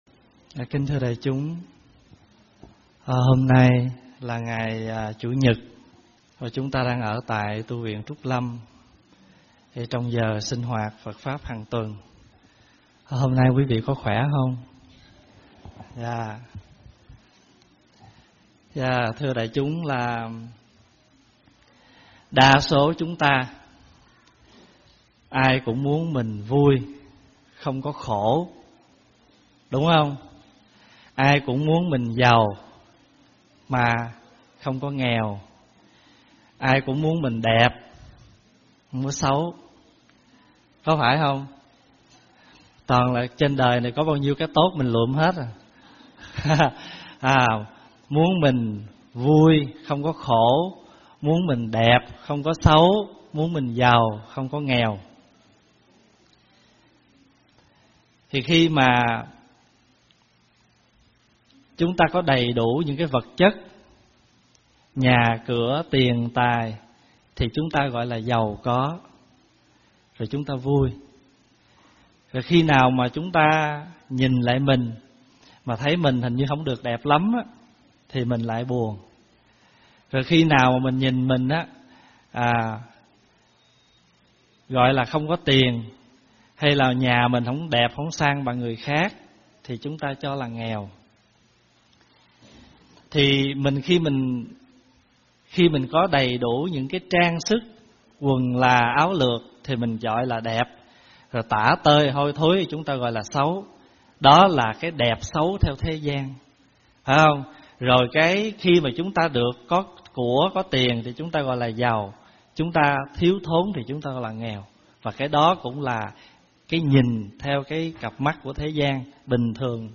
Mời quý phật tử nghe mp3 thuyết pháp Đẹp Xấu Giàu Nghèo Do Đâu ?